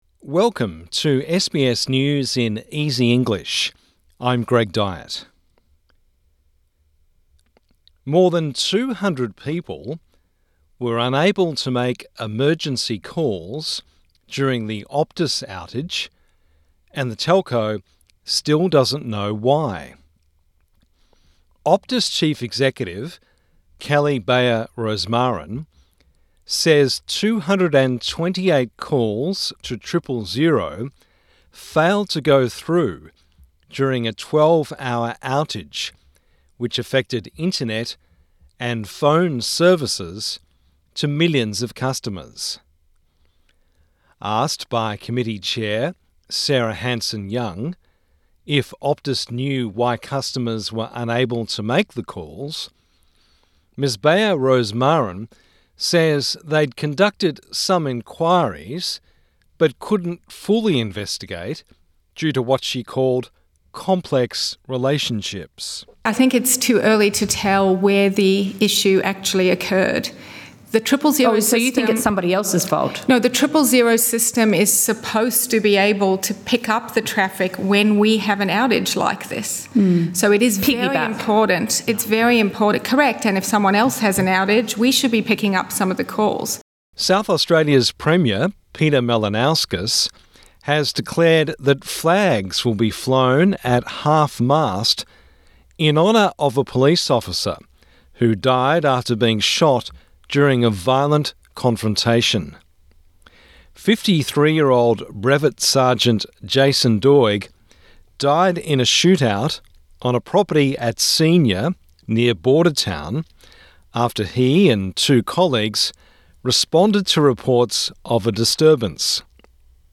A daily five minute news wrap for English learners and people with disability.